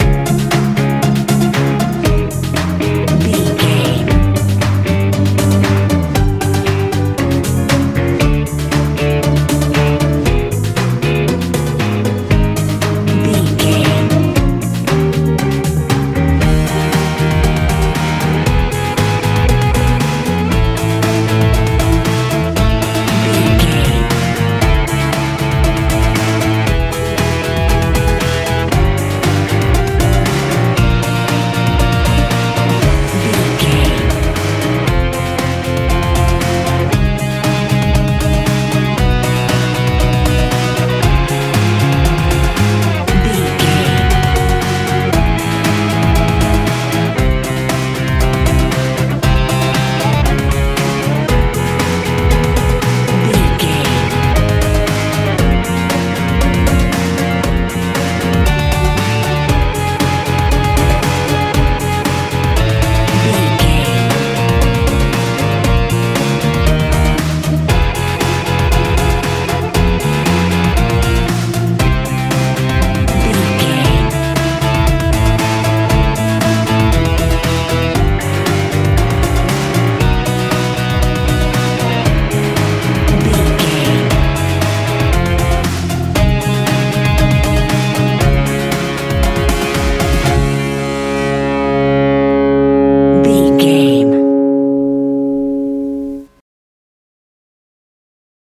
modern dance feel
Ionian/Major
C♯
electric guitar
bass guitar
drums
synthesiser
80s
90s